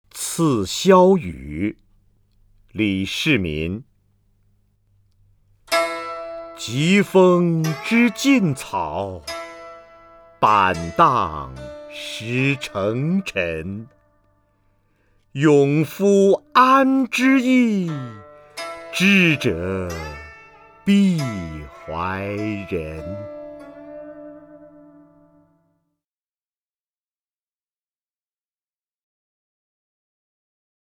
瞿弦和朗诵：《赠萧瑀》(（唐）李世民) （唐）李世民 名家朗诵欣赏瞿弦和 语文PLUS
（唐）李世民 文选 （唐）李世民： 瞿弦和朗诵：《赠萧瑀》(（唐）李世民) / 名家朗诵欣赏 瞿弦和